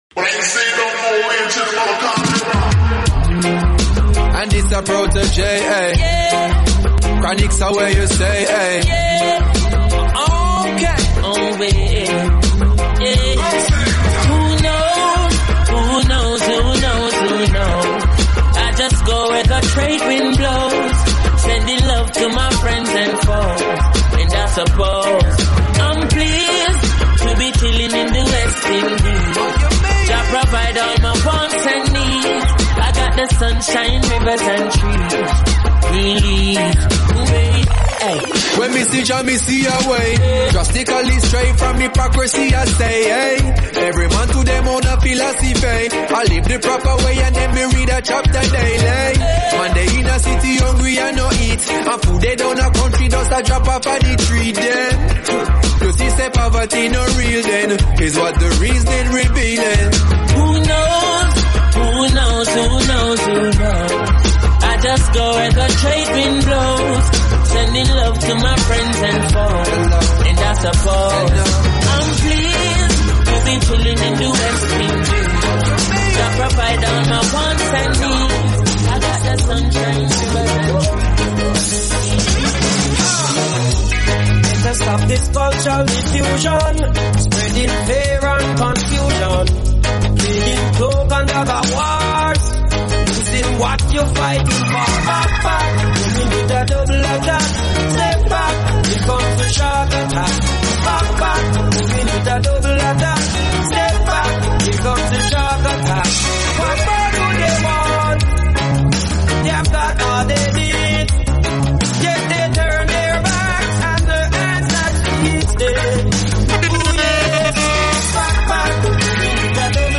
reggae music